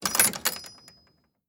Free Fantasy SFX Pack
Doors Gates and Chests
Gate Open.wav